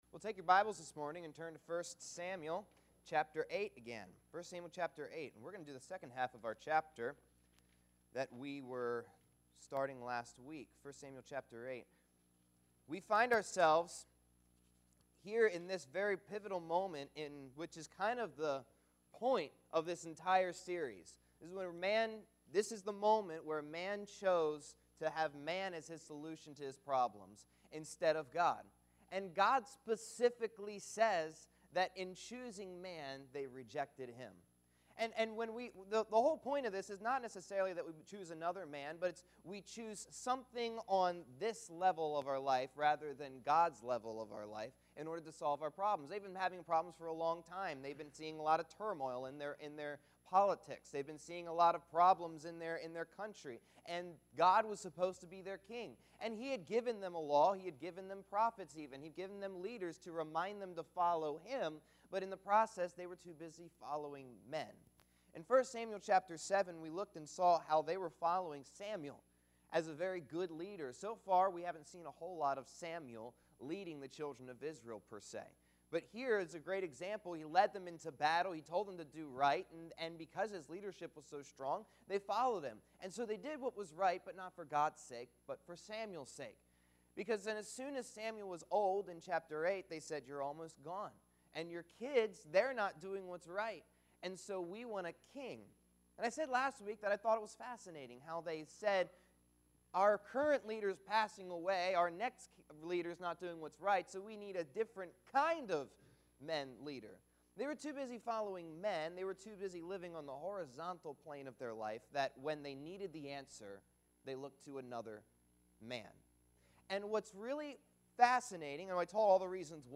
Passage: I Samuel 8:7-22 Service Type: Auditorium Bible Class « Rejoicing In the Hope of Heaven For Such a Time as This